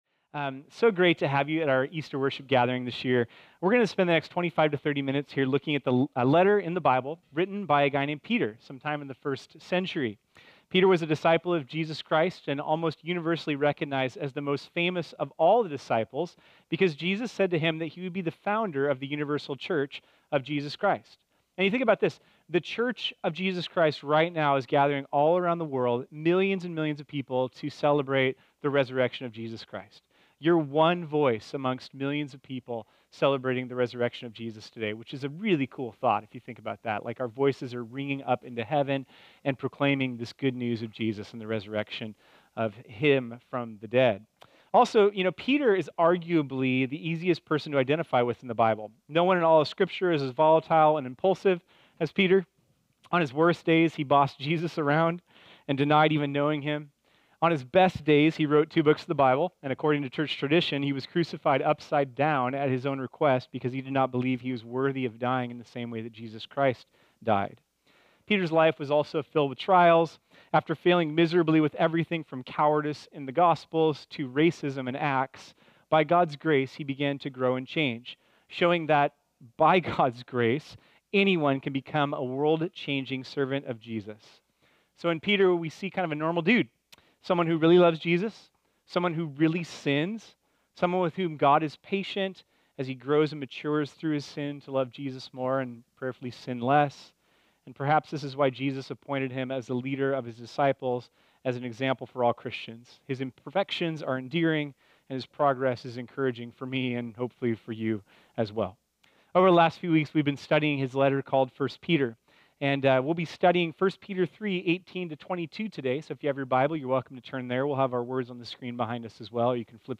This sermon was originally preached on Sunday, April 1, 2018.